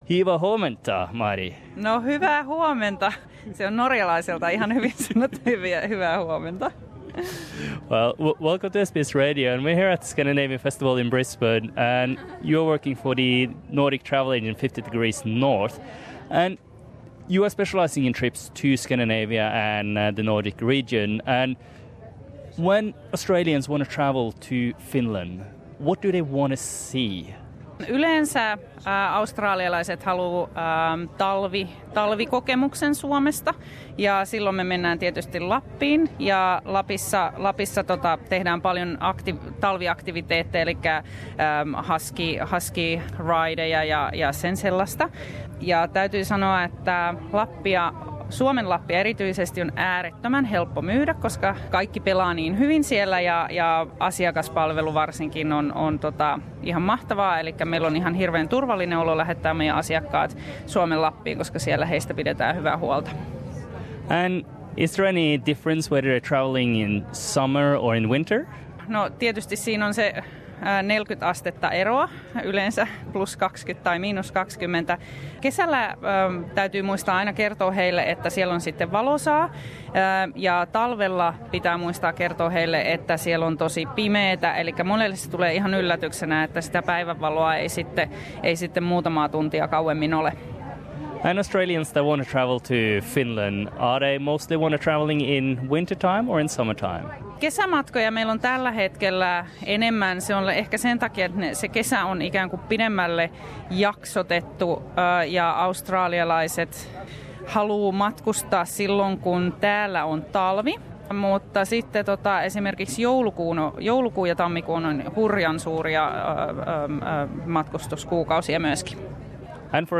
Brisbanen skandinaavinen festivaali - Australialaiset suomenmatkailijat
Radio 4EB stand Source: SBS Norwegian